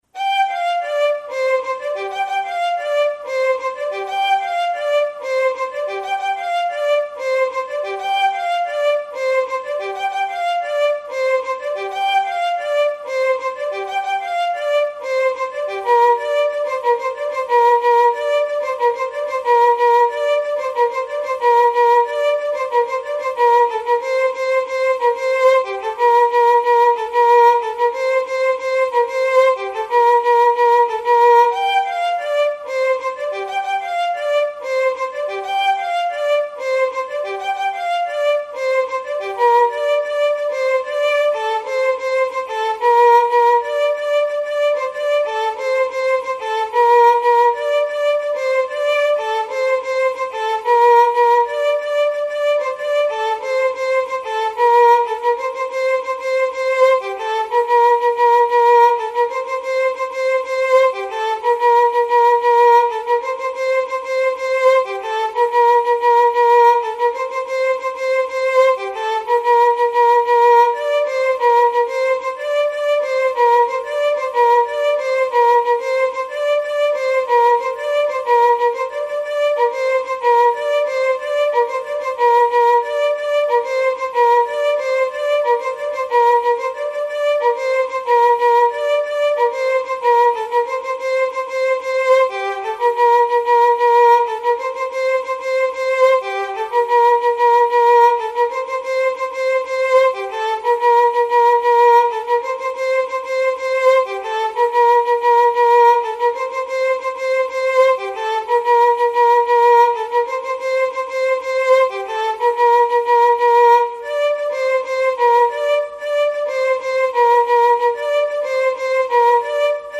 ویولون
محلی جنوبی